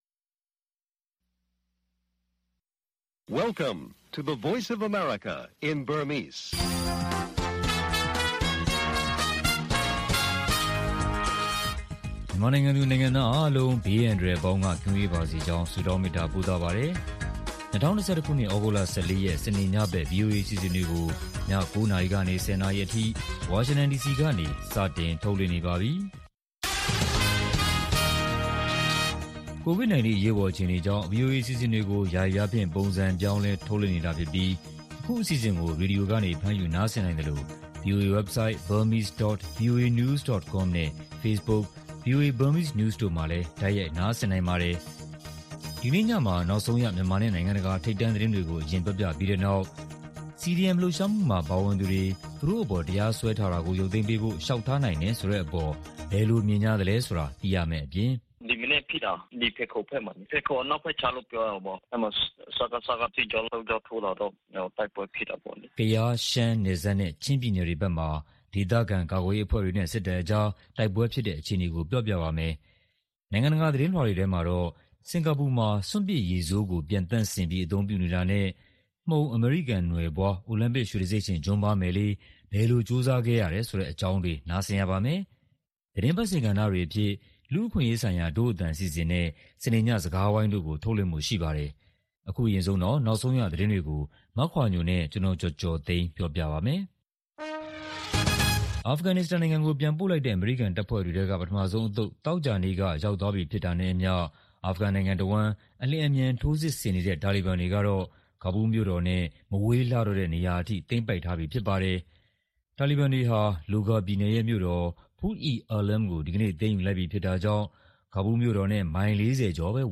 VOA ရေဒီယိုညပိုင်း ၉း၀၀-၁၀း၀၀ တိုက်ရိုက်ထုတ်လွှင့်မှု(သြဂုတ် ၁၄၊၂၀၂၁)